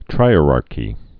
(trīə-rärkē)